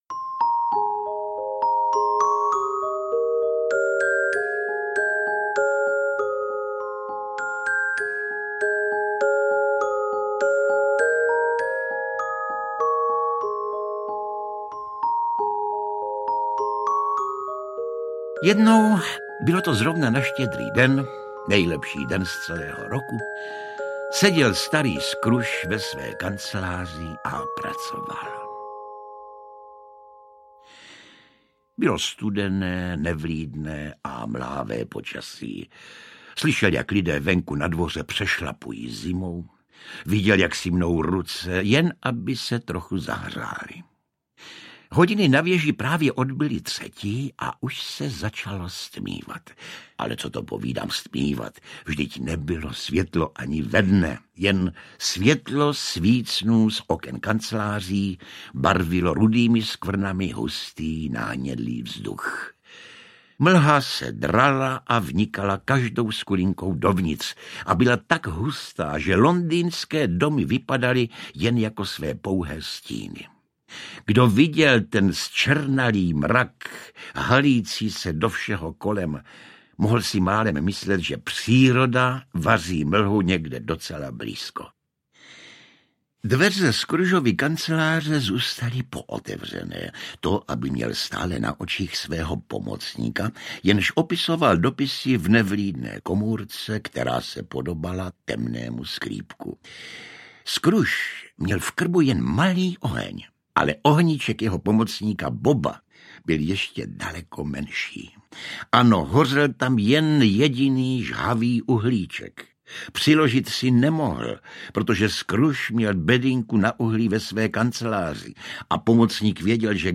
Vánoční koleda audiokniha
Josef Somr je v roli skrblíka Scrooge excelentní a jeho strhující vyprávění činí z této audioknihy jedinečný posluchačský zážitek. Neopakovatelnou atmosféru Vánoc "ve staré dobré Anglii" dodává nahrávce i originální hudba.
• InterpretJosef Somr